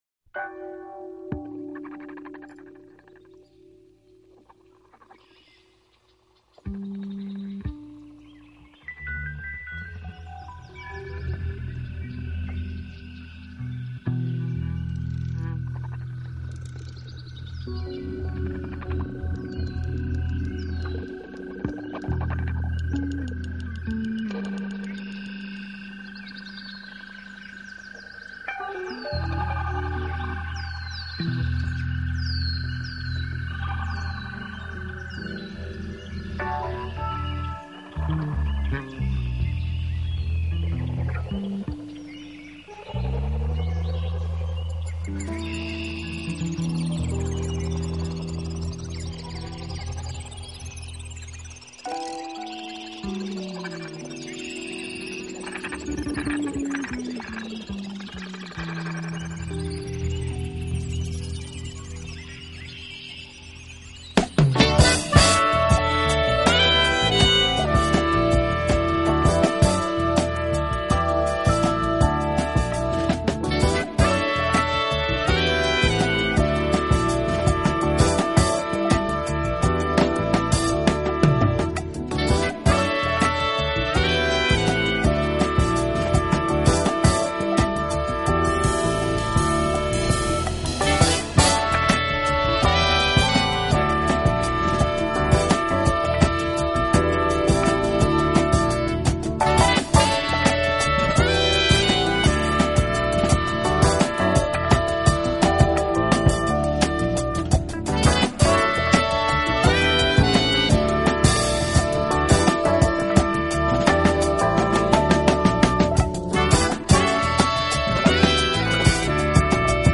他的演奏风格包容了Jazz、R&B、Pop，不